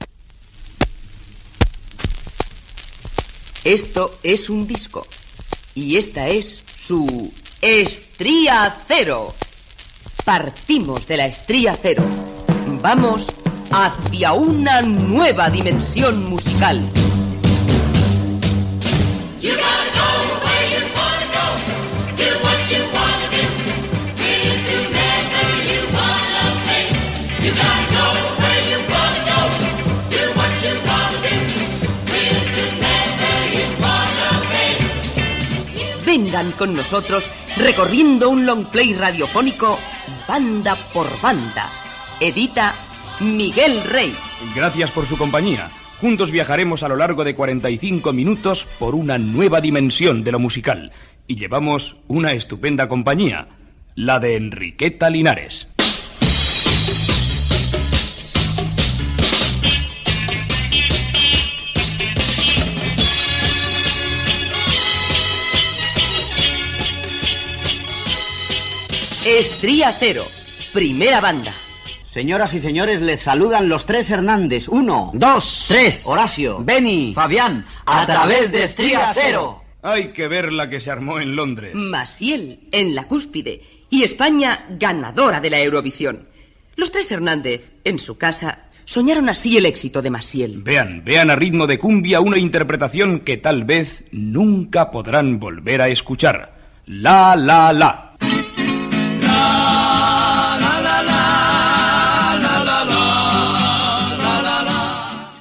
Musical